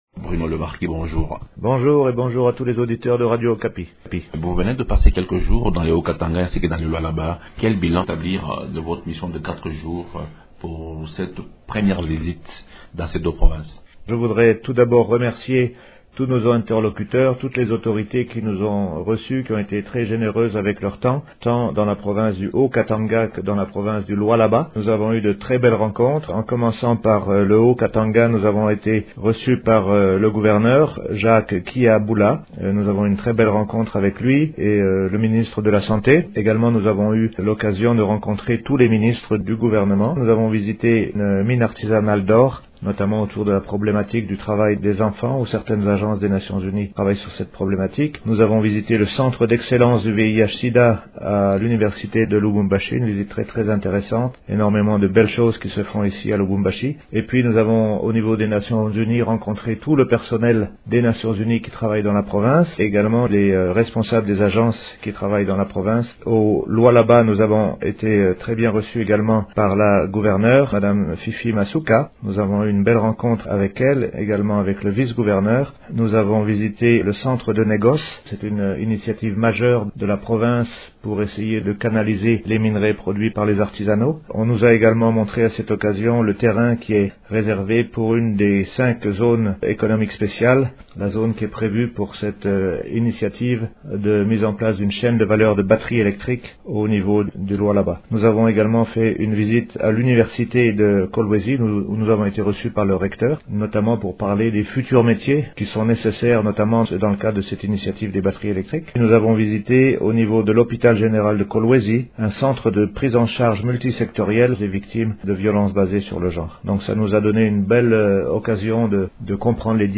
Au cours d’une interview accordée à Radio Okapi, il a exprimé la volonté des agences des Nations Unies de fournir un soutien pour le développement de ces régions.